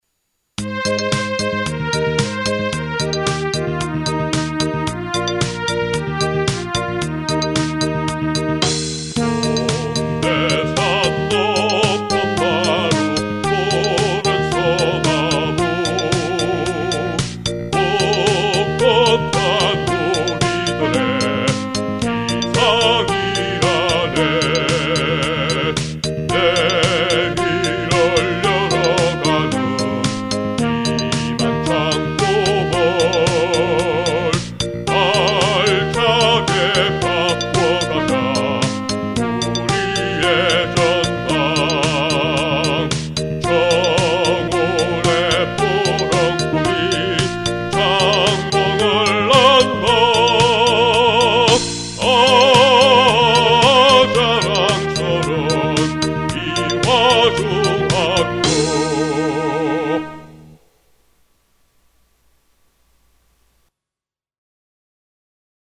이화중학교 교가 음원 :울산교육디지털박물관